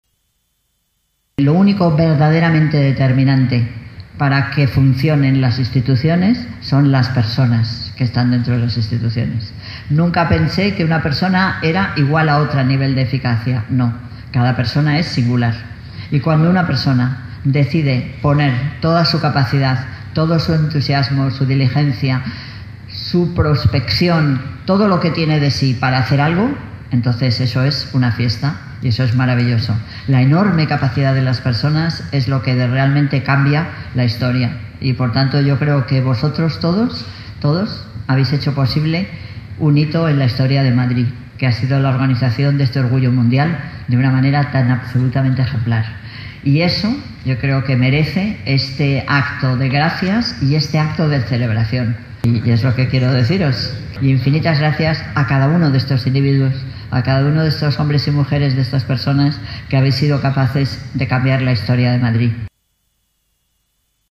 Nueva ventana:Palabras de agradecimiento de Manuela Carmena a los organizadores del WorldPride